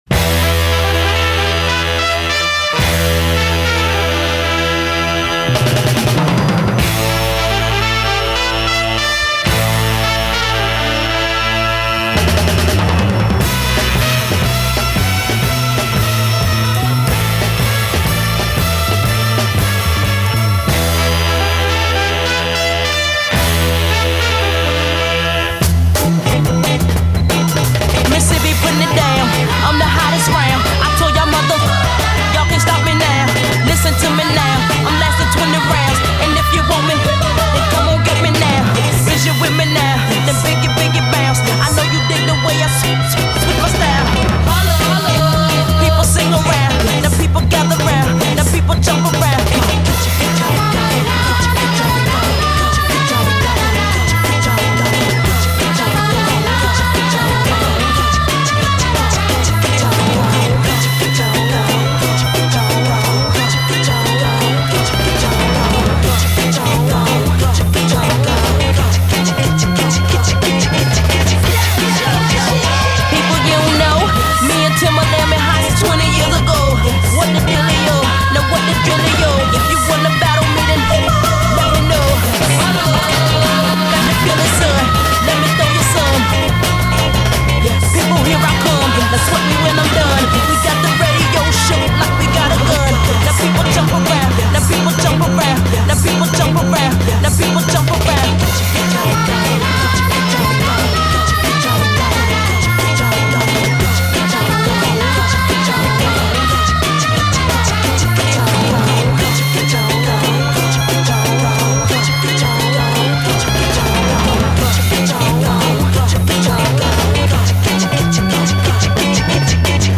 MASHUPS A-GO-GO
a groovy '60s discotheque feel; added beatz help.